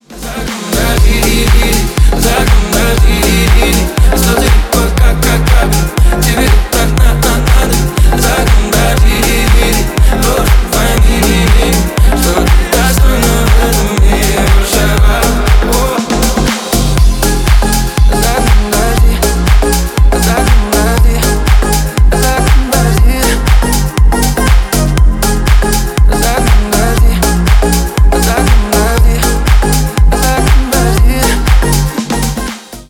• Качество: 320 kbps, Stereo
Ремикс
Танцевальные